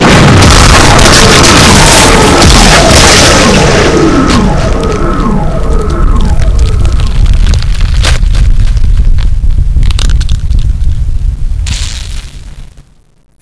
pain25_1.wav